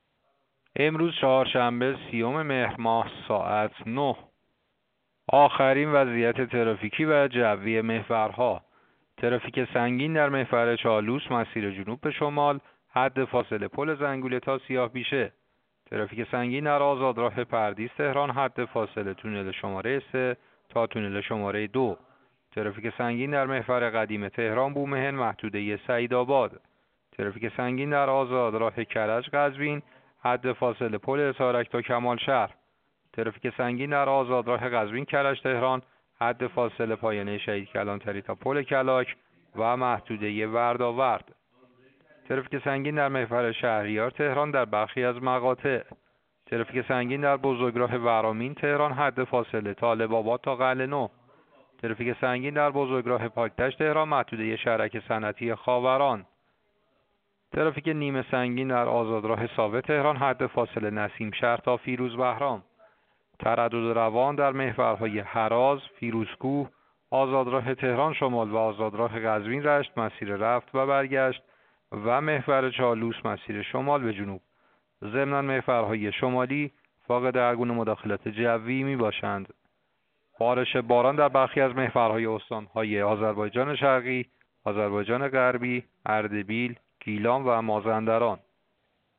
گزارش رادیو اینترنتی از آخرین وضعیت ترافیکی جاده‌ها ساعت ۹ سی‌ام مهر؛